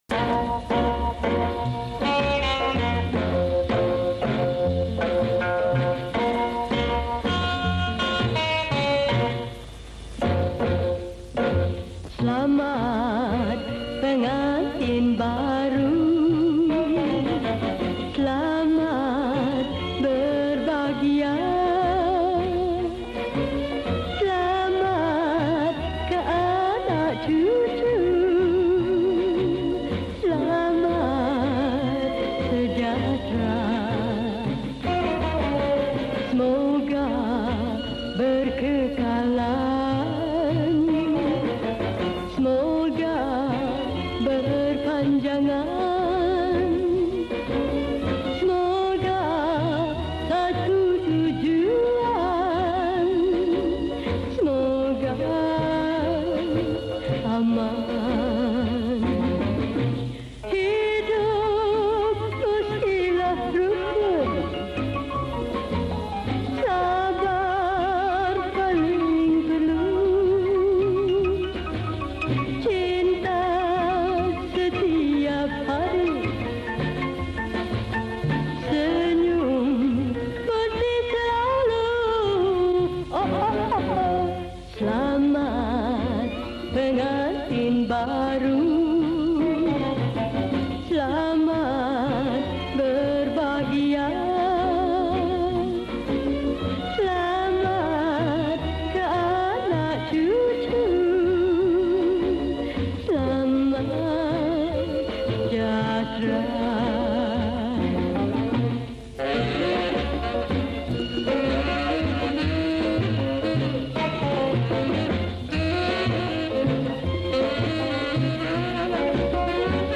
Malay Song
Skor Angklung